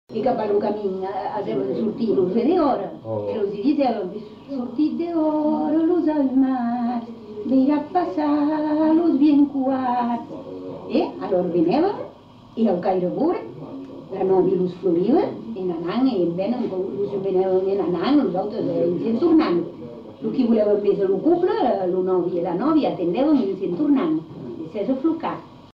Aire culturelle : Marmandais gascon
Lieu : Tonneins
Genre : chant
Effectif : 1
Type de voix : voix de femme
Production du son : chanté